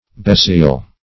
Besaiel \Be*saiel"\, Besaile \Be*saile"\, Besayle \Be*sayle"\,